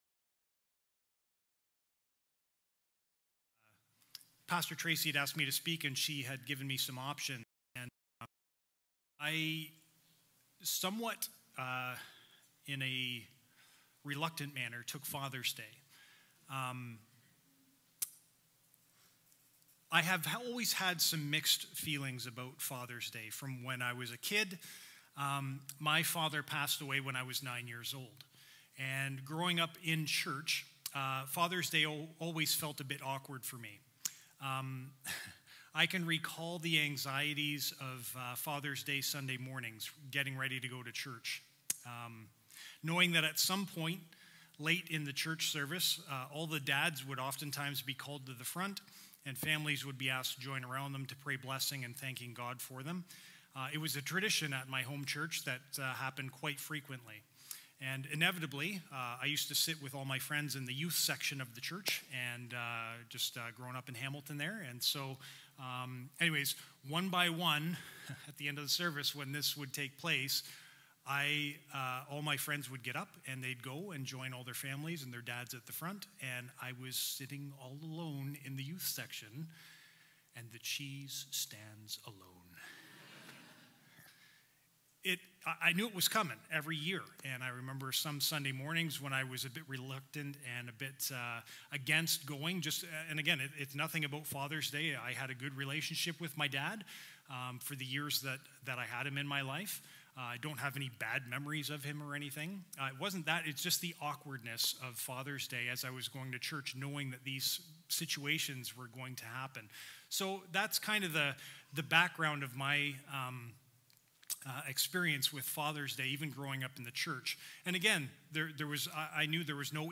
Sunday+Sermon+Template.m4a